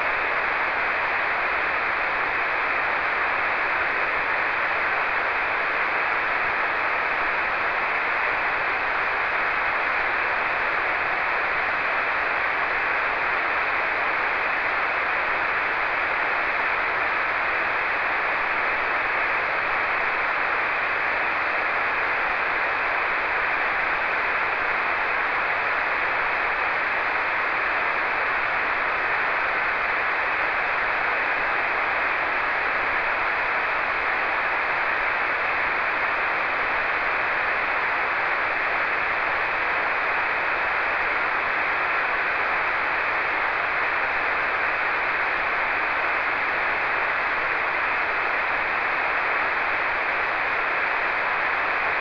Prove di ascolto WSJT - 8,9 aprile 2011
Antenna: 16JXX, Apparato: FT897
nessun preamplificatore
N.B.: Solo nel primo file si può ascoltare un flebile "suono"... per tutti gli altri solo utilizzando WSJT si ha evidenza dei segnali ricevuti.